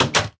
Minecraft Version Minecraft Version latest Latest Release | Latest Snapshot latest / assets / minecraft / sounds / block / chest / close_locked.ogg Compare With Compare With Latest Release | Latest Snapshot
close_locked.ogg